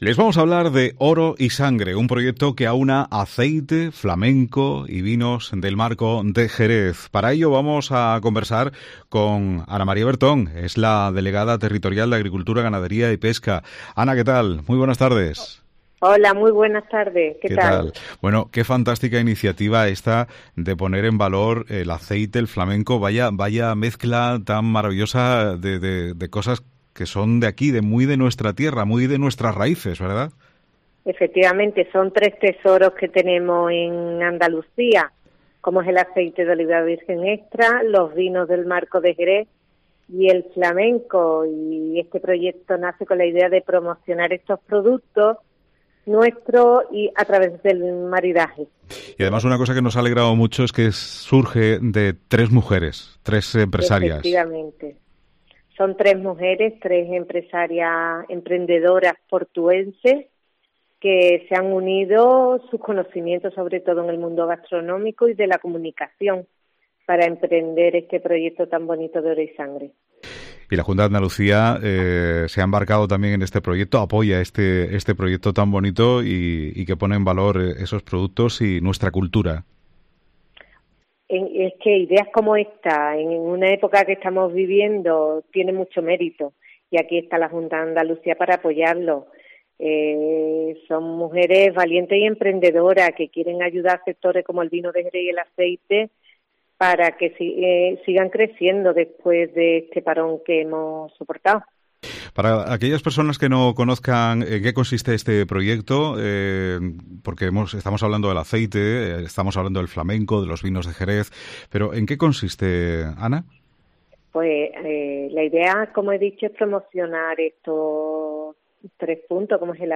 Ana María Bertón, Delegada de Agricultura, Ganadería y Pesca de la Junta de Andalucía en Cádiz
La Delegada de Agricultura, Ganadería y Pesca de la Junta de Andalucía Ana María Bertón, habla en COPE de Oro y Sangre, un proyecto que aúna Aceite de Oliva Virgen Extra, Vinos del Marco de Jerez y Flamenco, y que se dio a conocer en El Puerto de Santa María con la colaboración de la delegación territorial de Agricultura, Ganadería, Pesca y Desarrollo Sostenible y de la concejalía de Turismo portuense.